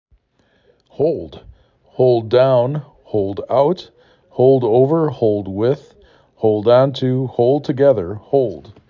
4 Letters, 1 Syllable
h O l d